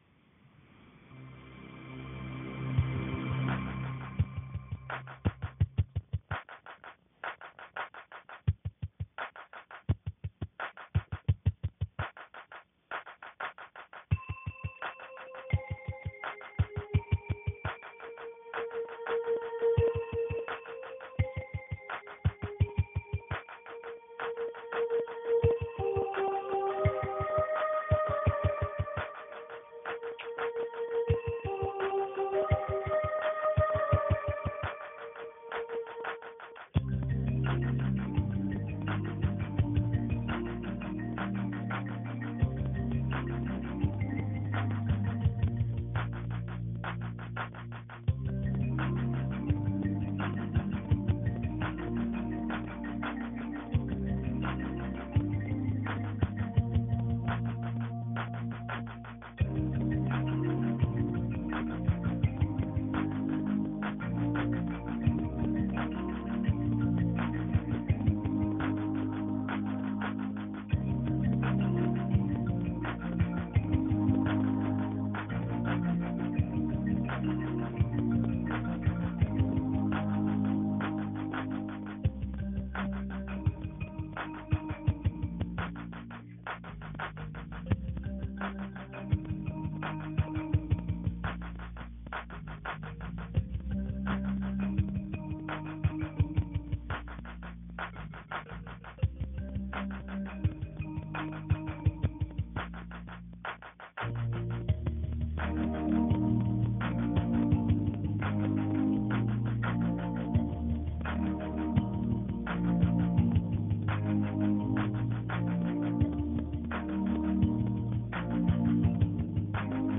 holdmusic.m4a